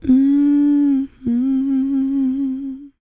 E-CROON P302.wav